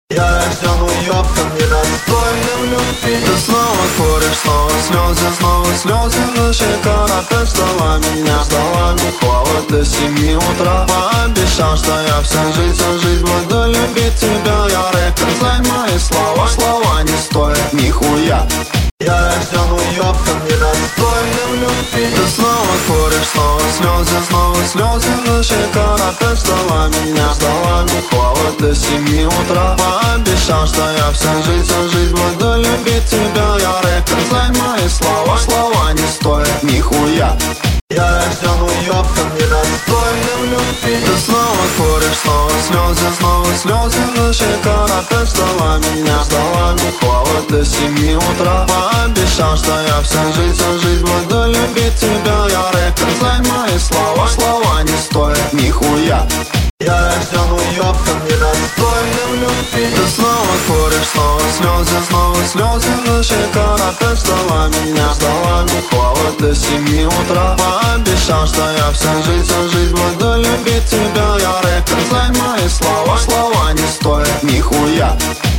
Мэшап ТикТок Ремикс